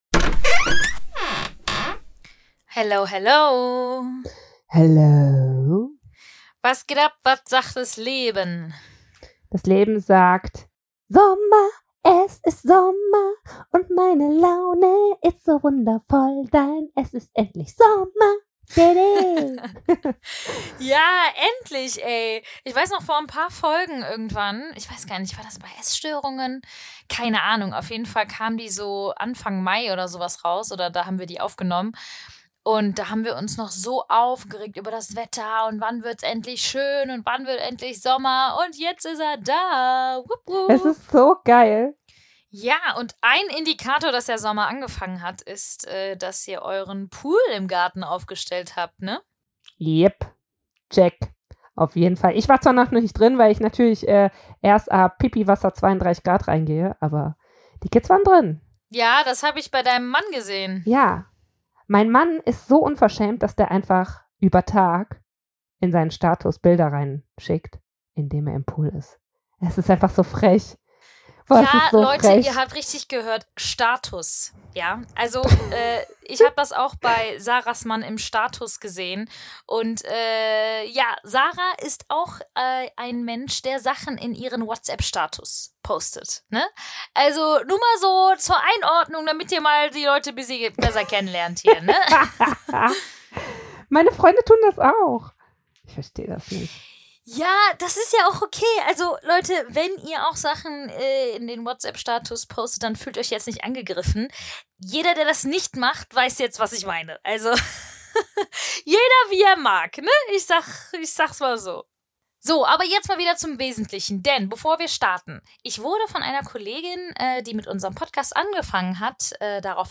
Die Schwestern sprechen darüber, wie eine gestörte Bindung mit Persönlichkeitsstörungen zusammenhängt und warum eine Bindungsstörung nur im Kleinkindalter entwickelt werden kann.
Wir sprechen offen und locker über diese Themen - gegen Stigmatisierung und für Offenheit und Toleranz.